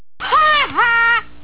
Nelson's laugh
haha.wav